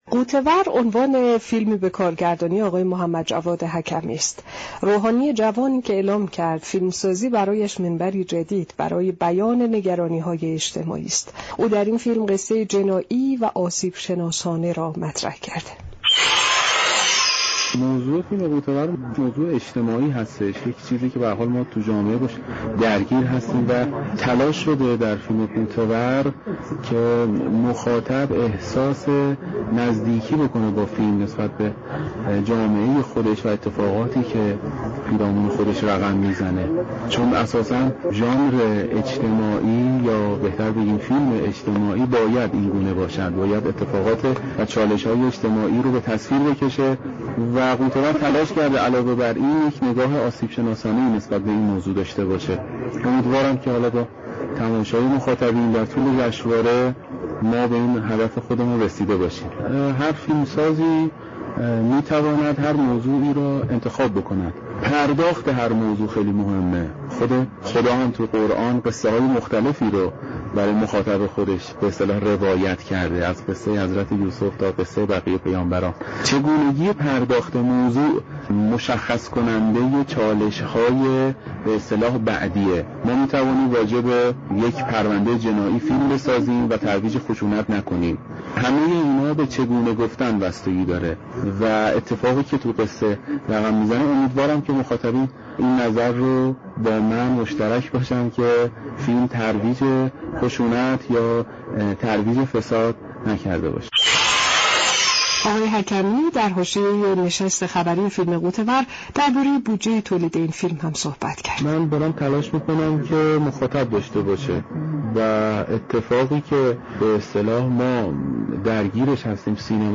گفت‌و‌گو كرده است.
برنامه موج سیمرغ از 12 تا 22 بهمن هر شب ساعت 20:00 از رادیو ایران پخش می‌شود.